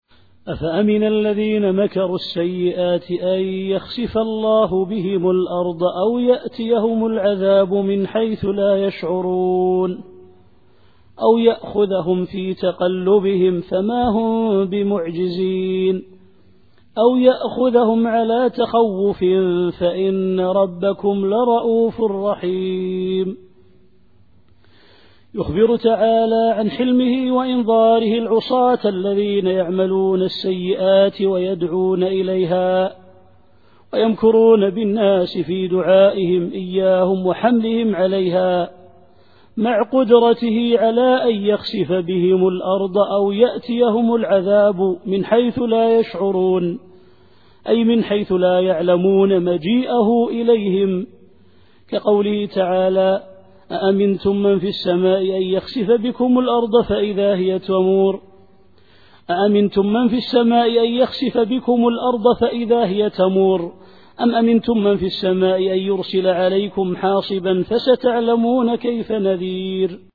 التفسير الصوتي [النحل / 45]